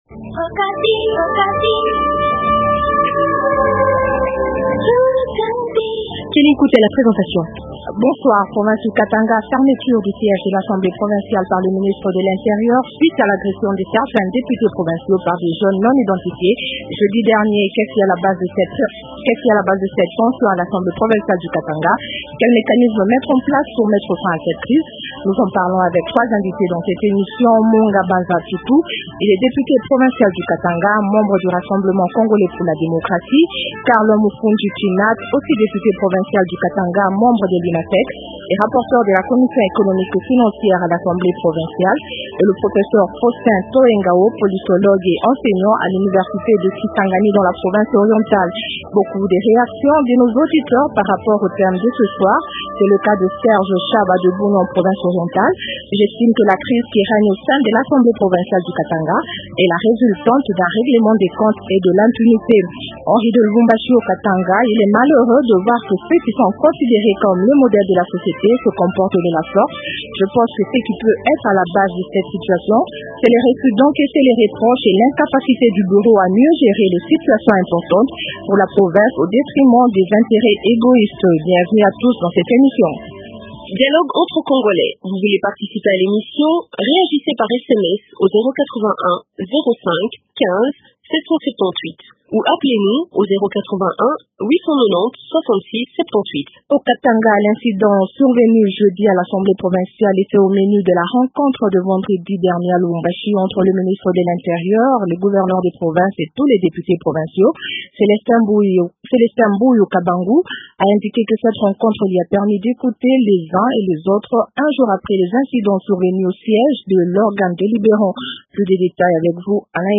-Karl Mufundji Tshinat, député provincial du Katanga, membre de l’Unafec et rapporteur de la commission économique et financière de l’assemblée provinciale.